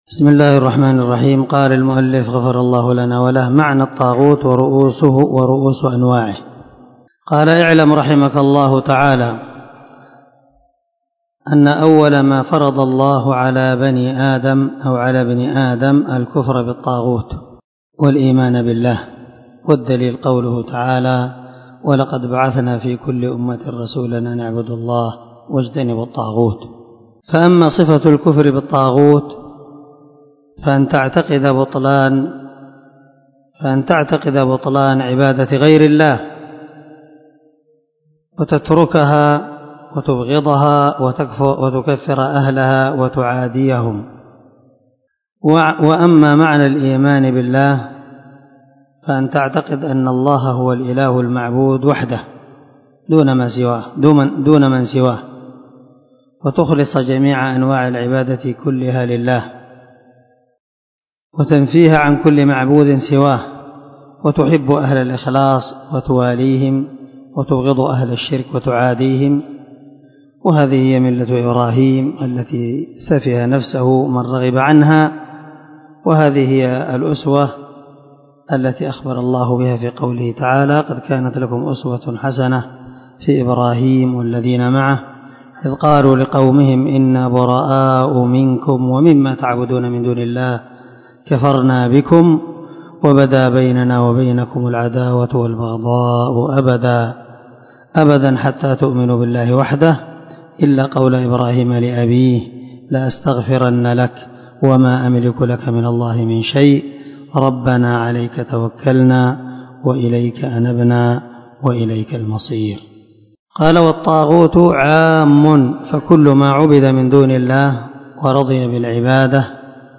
🔊الدرس 42 معنى الطاغوت ورؤوس أنواعه